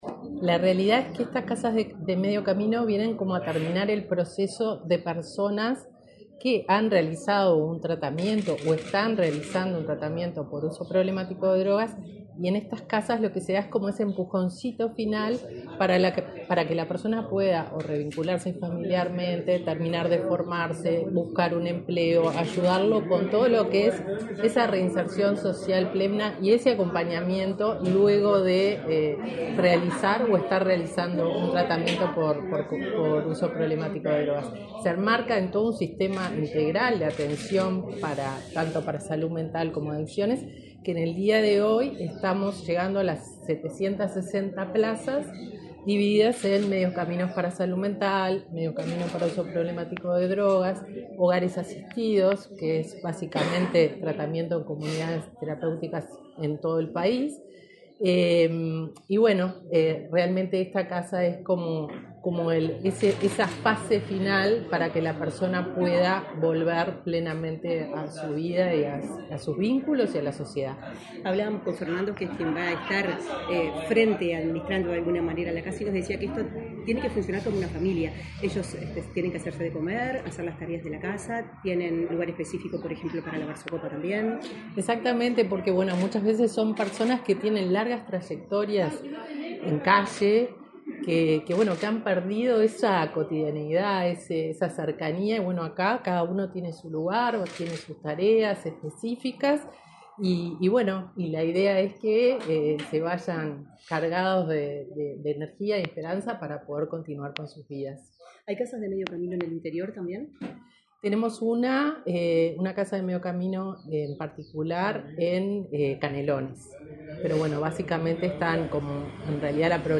Declaraciones de la directora nacional de Protección Social, Fernanda Auersperg
El Ministerio de Desarrollo Social inauguró una casa de medio camino en Montevideo. Se trata del cuarto dispositivo para personas que presentan problemas asociados al uso de drogas La directora nacional de Protección Social, Fernanda Auersperg, dialogó con la prensa acerca de la importancia de este nuevo servicio.